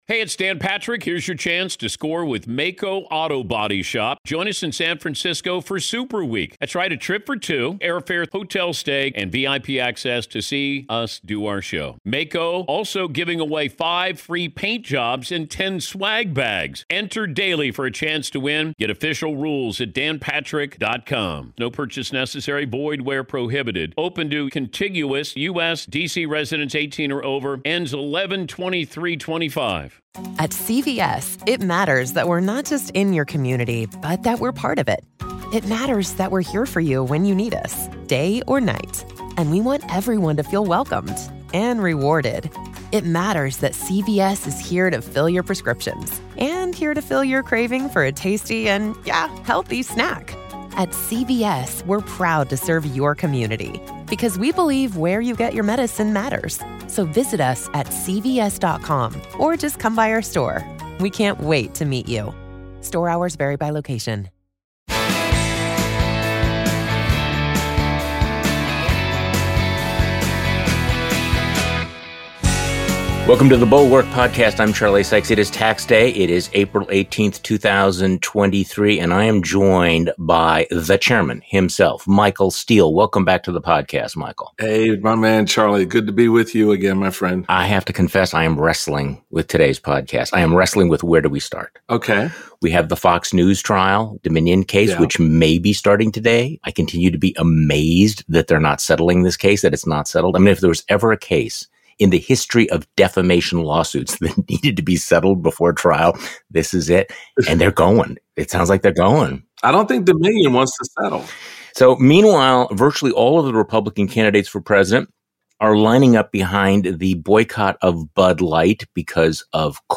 Plus, even Trump is trolling DeSantis about his Disney flop. Michael Steele joins Charlie Sykes today.